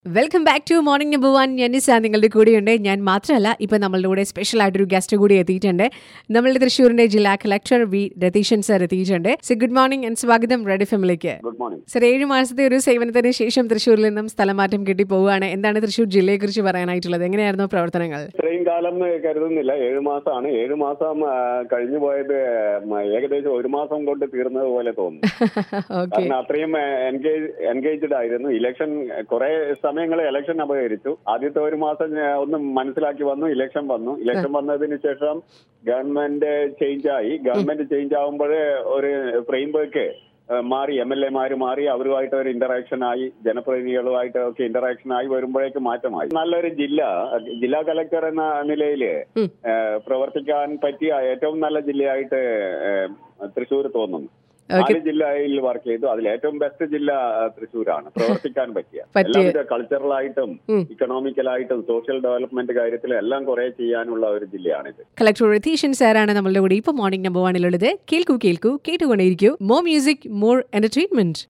Morning No1|കലക്ടർമാർക്ക് സ്ഥലംമാറ്റം |തൃശ്ശൂർ കലക്ടർ രതീശൻ സംസാരിക്കുന്നു